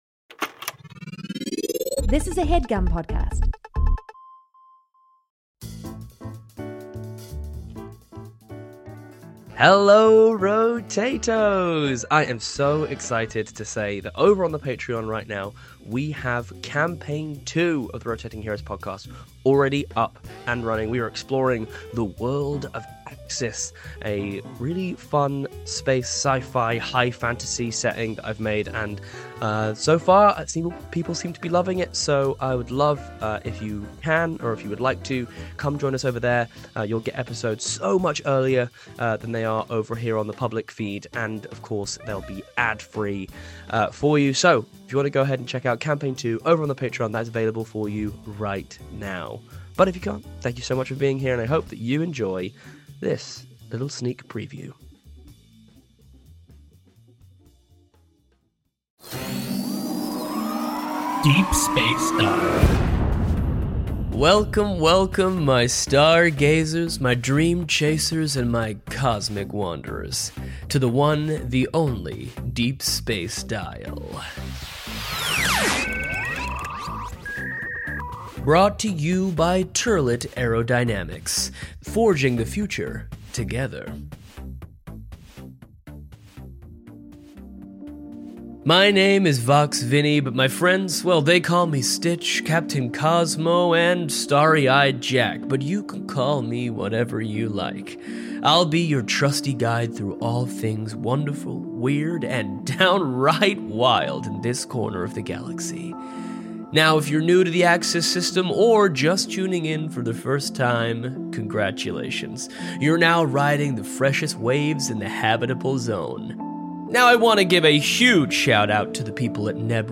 Celebrate with us as we share a sneak peek at the new campaign with our in-world radio series, Deep Space Dial.